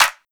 • Studio Hand Clap F# Key 15.wav
Royality free hand clap - kick tuned to the F# note. Loudest frequency: 3179Hz
studio-hand-clap-f-sharp-key-15-V4X.wav